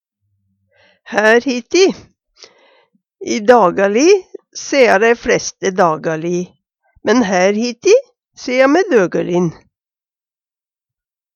hær hiti - Numedalsmål (en-US)